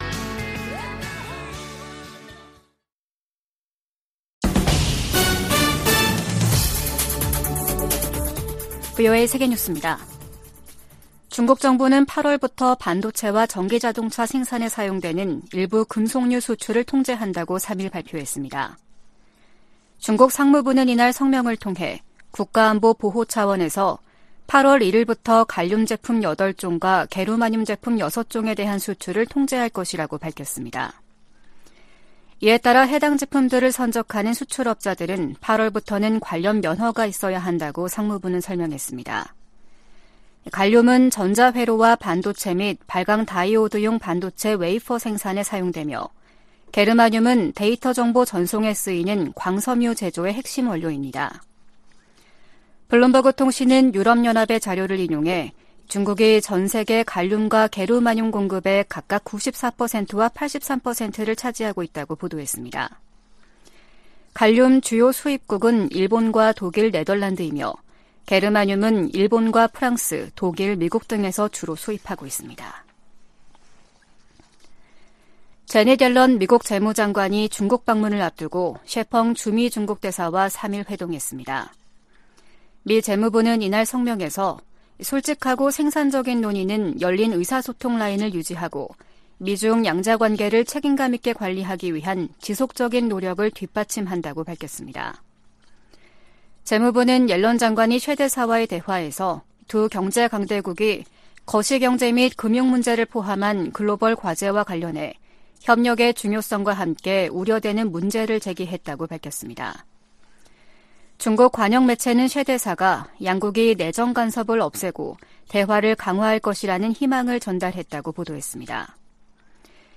VOA 한국어 아침 뉴스 프로그램 '워싱턴 뉴스 광장' 2023년 7월 5일 방송입니다. 북한이 지난 2016년 2월 7일 발사한 '광명성 4호' 위성이 지구 대기권 재진입 후 소멸된 것으로 확인됐습니다. 미 핵추진 잠수함 미시간함이 한국에 이어 일본에 기항했습니다. 북한이 신종 코로나바이러스 감염증에 따른 마스크 의무를 해제한 것으로 알려지면서 국경 개방도 가능하다는 관측이 나오고 있습니다.